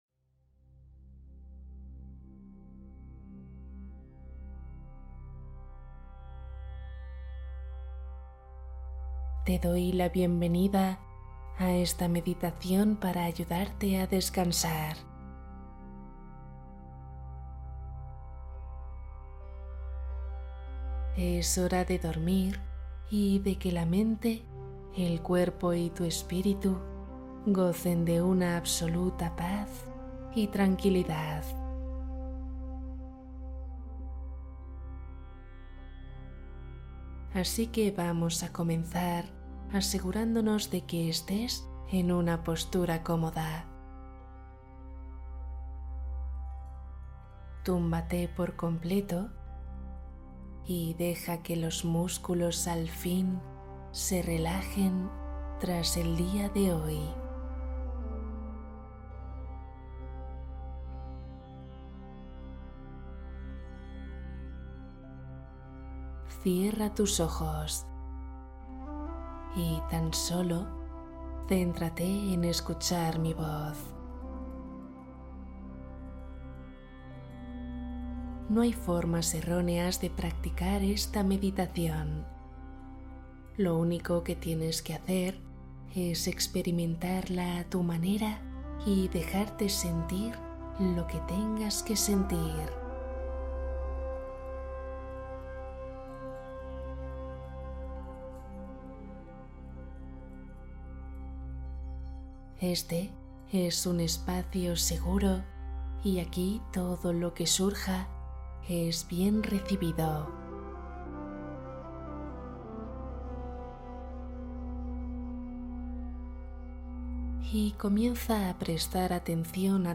Meditación matutina ☀ Para transformar tu energía del día